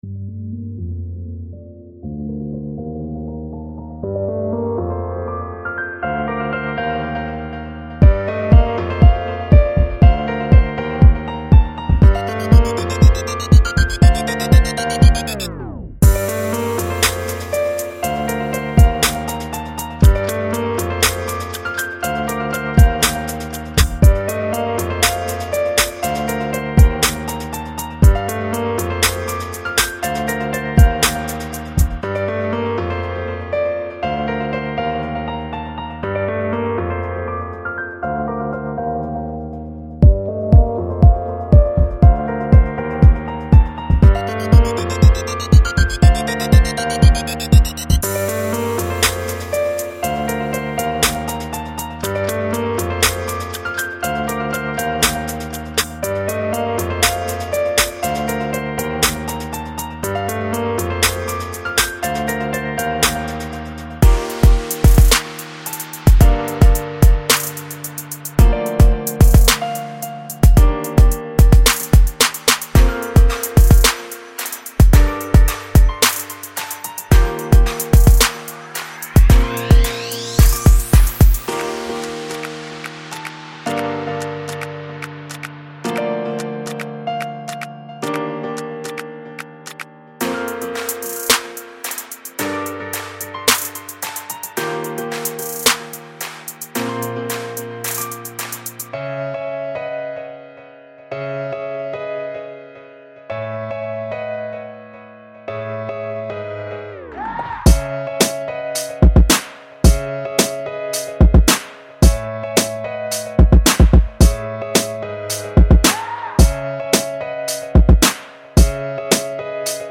包括25个额外的钢琴循环，作为套件的不错补充。
对于希望简单拖放的用户，“湿”文件包含音频演示中听到的所有效果处理。
该样本库还带有FX尾音，使您可以通过加在循环中的混响/延迟的衰减来结束乐句，这是另一个“生产者循环”独家产品功能。